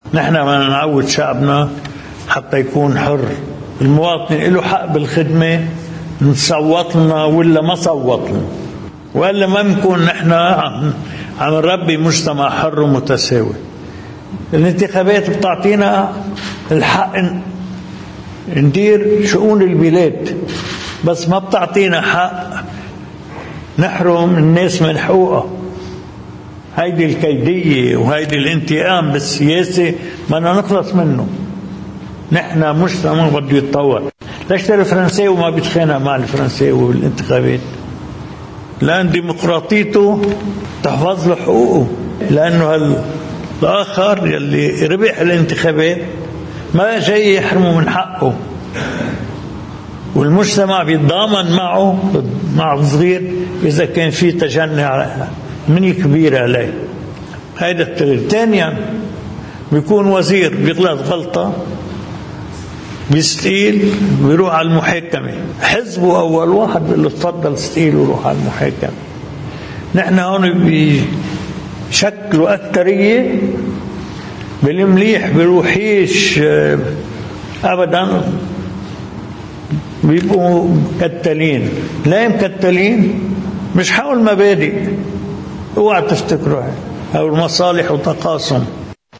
مقتطف من حديث الرئيس ميشال عون خلال استقباله وفد من فعاليات قرى وبلدات قضاء المتن، يضمّ رؤساء بلديات ومخاتير: